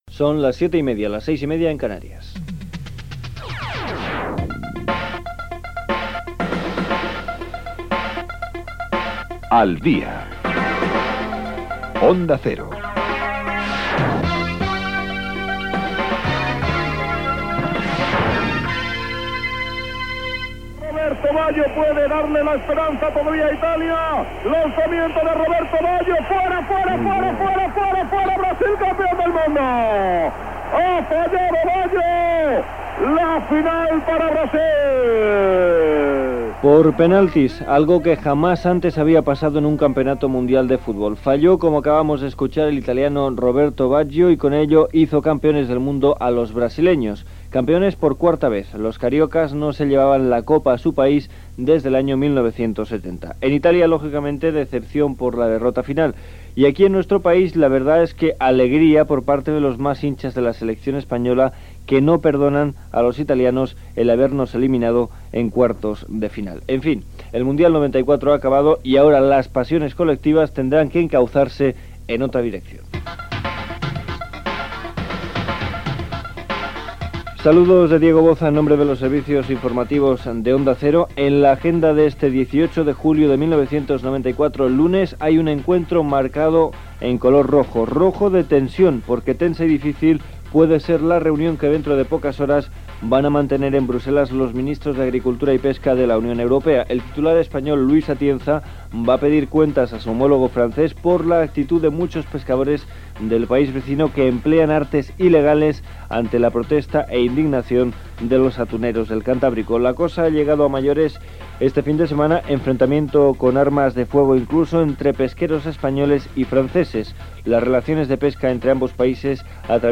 Hora, indicatiu del programa. Fragment de la transmissió de la final de la copa mundial de futbol 1994 entre Itàlia i Brasil que va guanyar Brasil i comentari. Salutació, data, titulars informatius i estat del temps
Informatiu